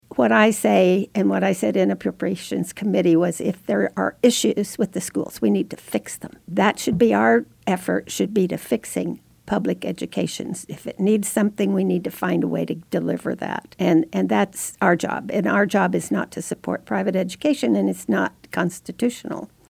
Rep. Carlin and Rep. Dodson appeared on KMAN’s In Focus Friday to recap the busy week in the Kansas Legislature.